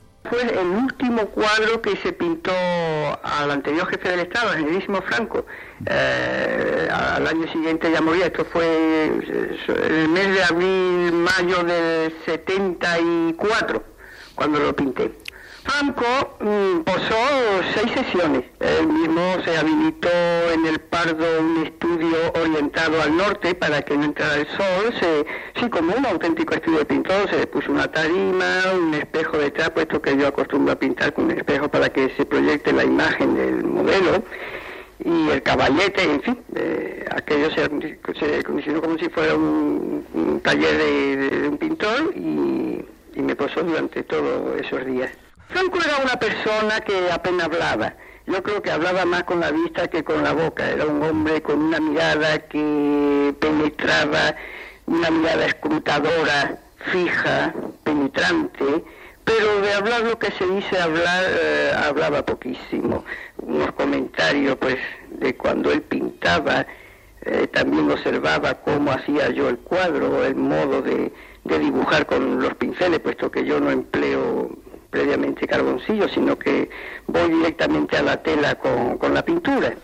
Info-entreteniment
Programa presentat per Luis del Olmo.